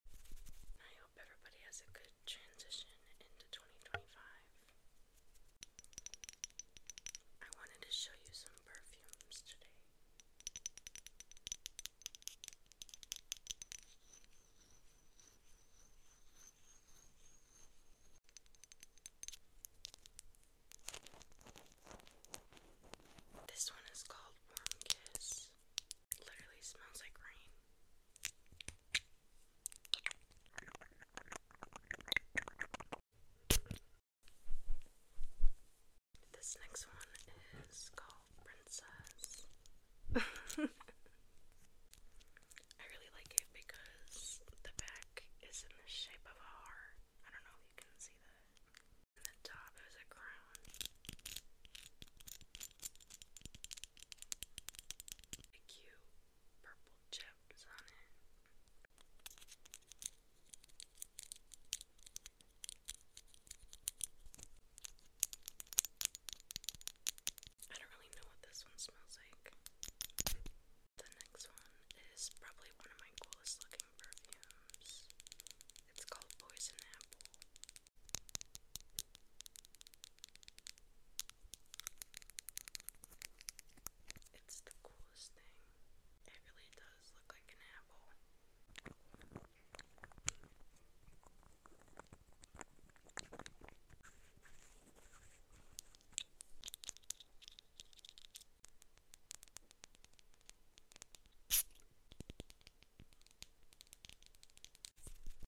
ASMR Perfume & Spraying Sounds sound effects free download
ASMR Perfume & Spraying Sounds 2 minutes (talking)